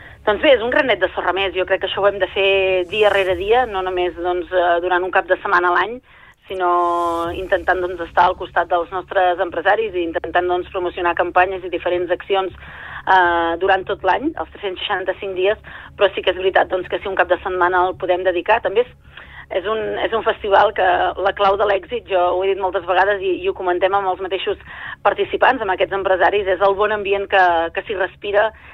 Al Supermatí hem parlat amb la regidora de Promoció econòmica de l’Ajuntament del municipi, Núria Cucharero, per parlar de la proposta i de les activitats que podrem trobar durant els tres dies de fira.